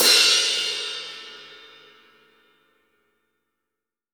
OZ20CRASH2-S.WAV